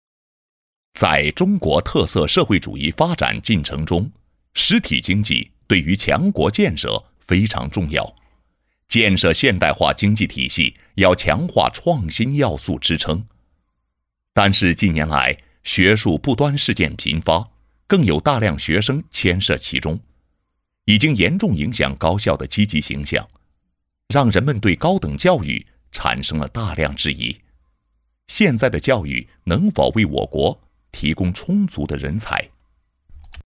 23 男国101_专题_学校_中国法制_沉稳 男国101
男国101_专题_学校_中国法制_沉稳.mp3